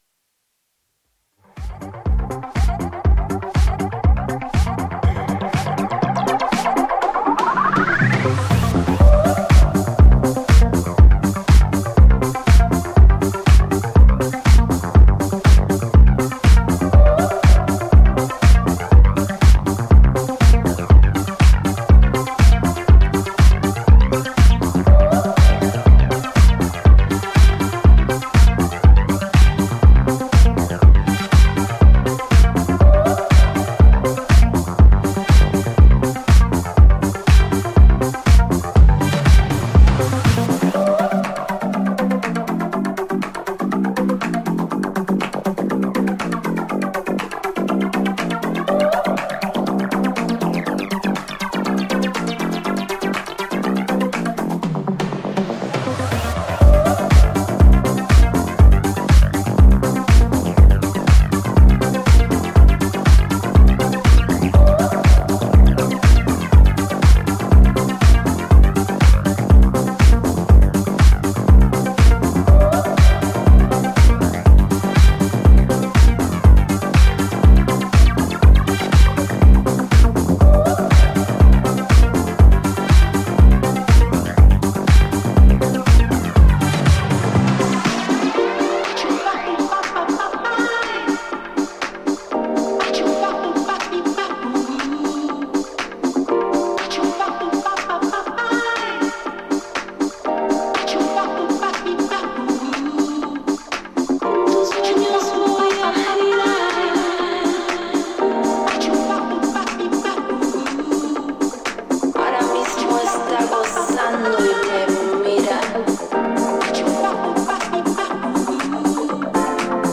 ディスコ/テック/バレアリックといったフロア受け抜群のハウスを展開するダンス・トラック全4曲を収録。
ジャンル(スタイル) HOUSE / DISCO HOUSE